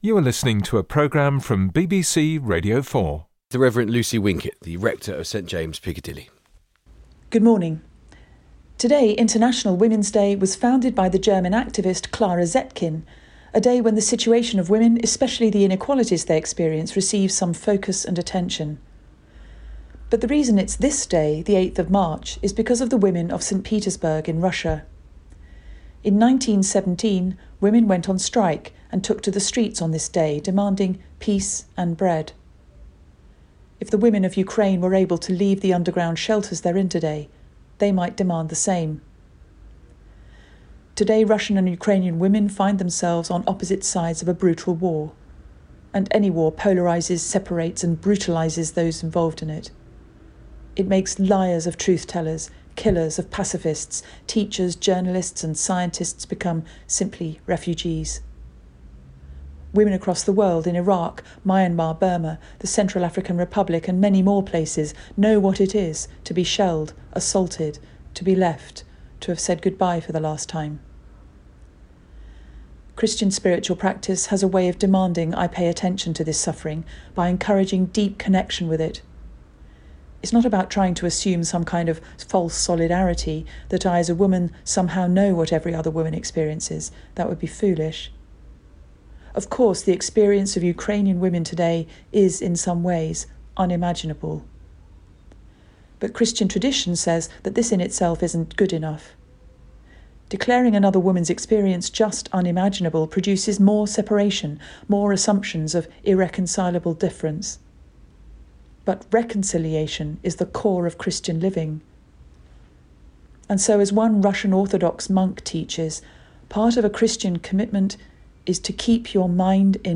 BBC Radio 4’s Religion & Spirituality podcast providing reflections from a faith perspective on issues and people in the news.